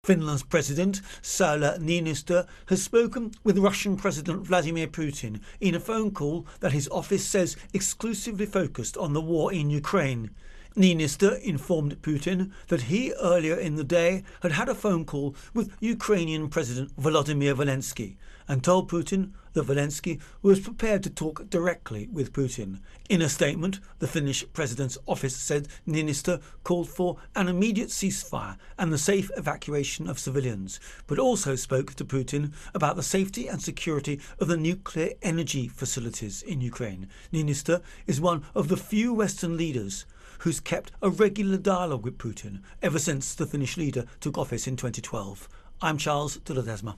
Russia-Ukraine-War-Finland Intro and Voicer